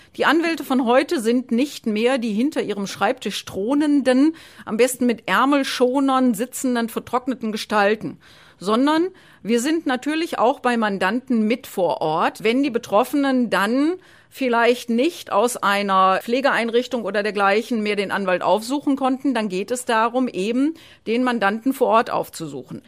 O-Ton: